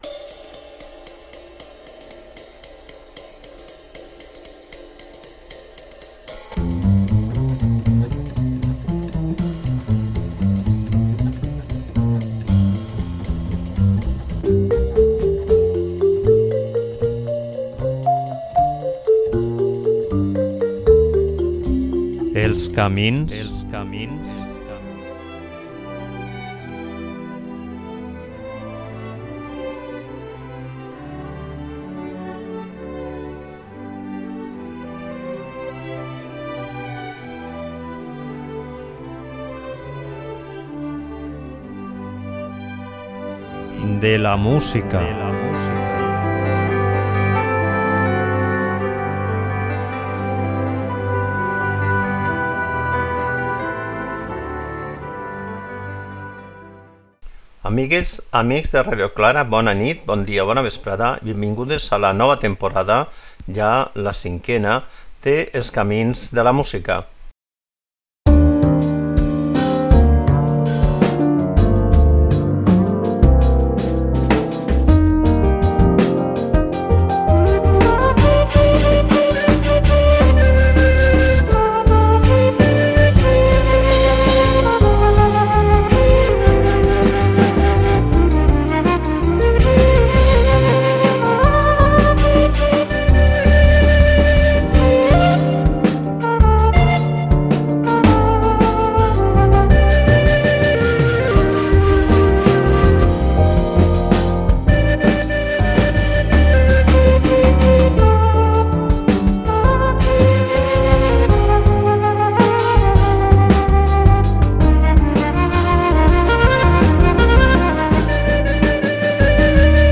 En el programa de huí anem a escoltar música de jazz interpretada o/i composada per artistes que han decidit trencar la baralla, de forma més radical o menys segons els casos.